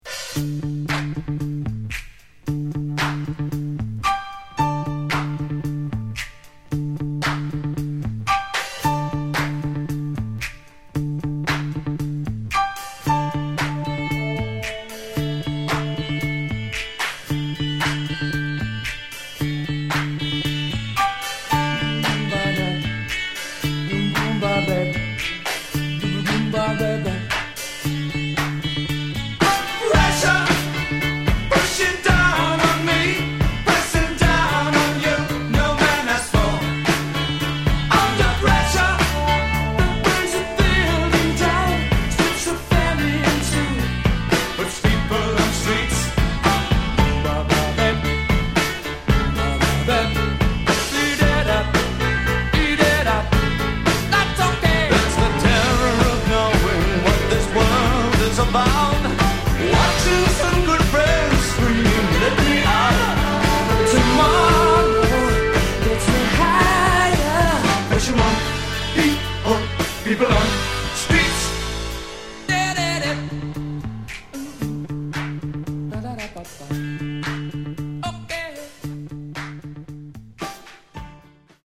Genre: Modern Rock